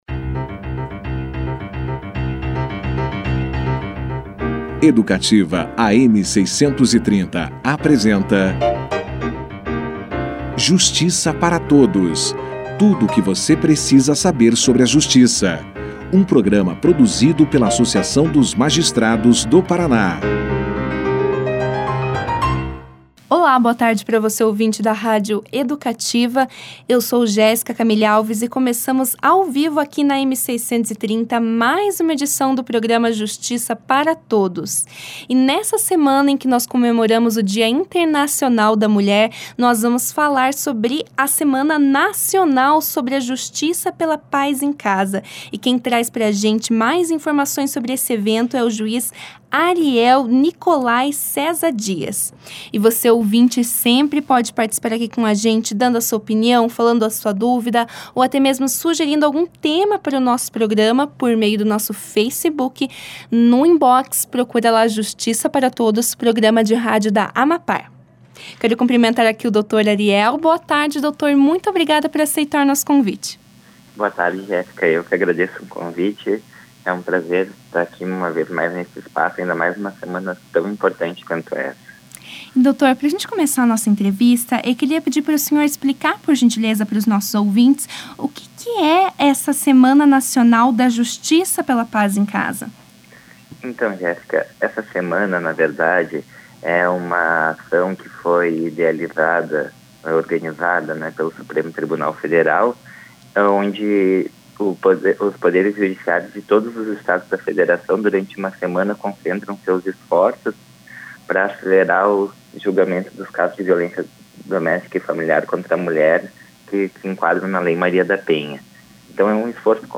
Na semana do dia Internacional da Mulher, o programa Justiça para Todos trouxe uma entrevista especial na rádio Educativa, AM 630, com o juiz de Direito Ariel Nicolai Cesa Dias, com o intuito de agregar a discussão sobre o combate à violência contra a mulher.